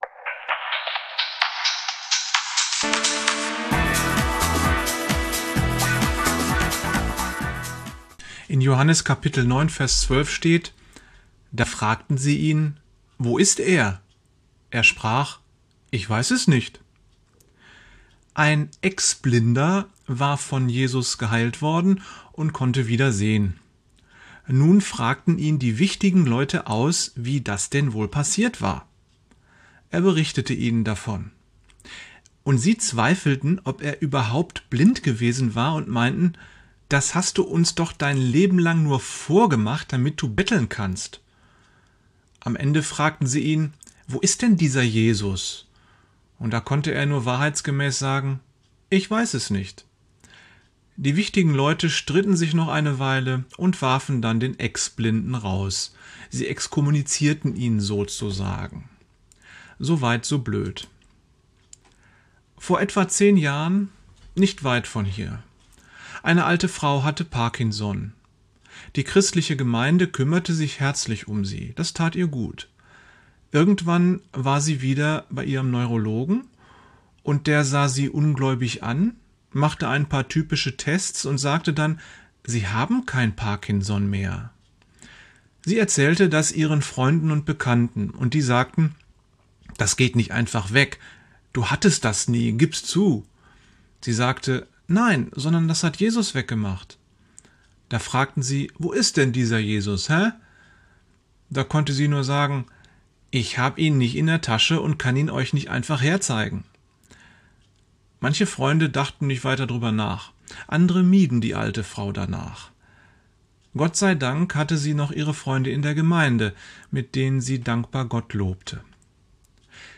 Sehr kurze Andachten (Shots) To Go.